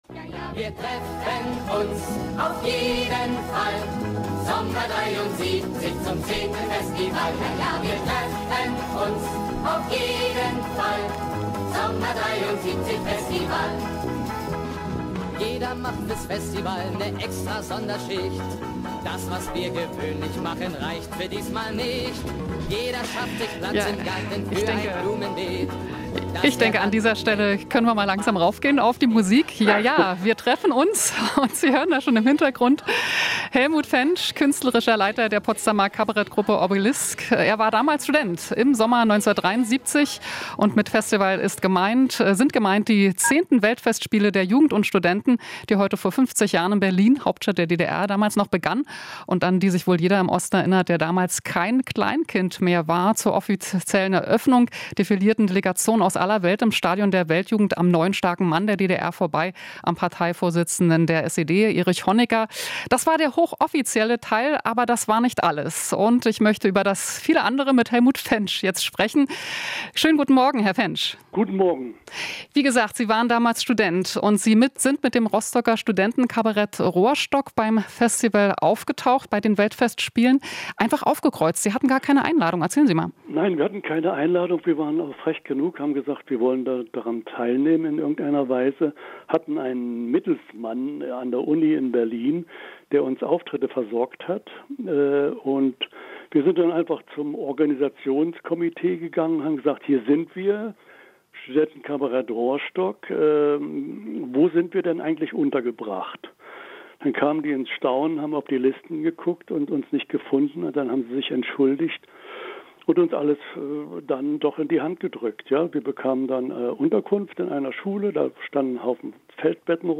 Interview - Teilnehmer über Weltfestspiele: Aufbruchstimmung war bald wieder vorbei